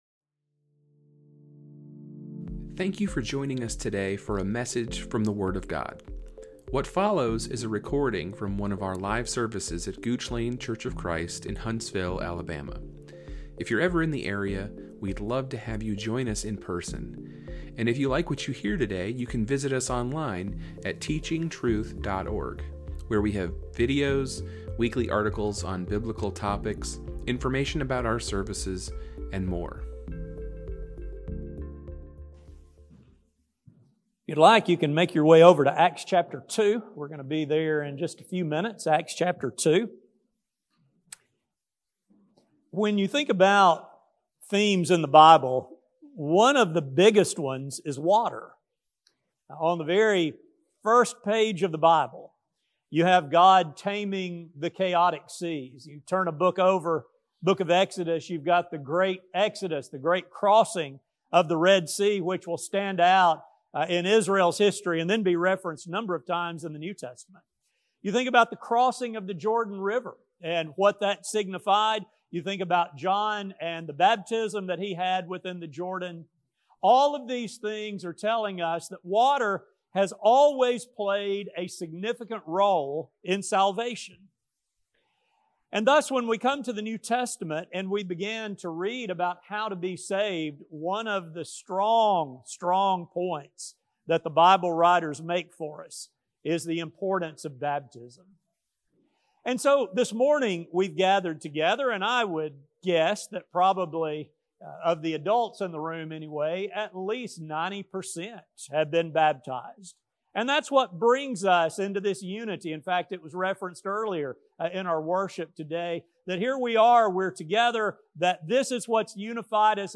This study will demonstrate how the two messages given by the apostle complement one another and provide a clear picture, both then and now, as to how one is saved. A sermon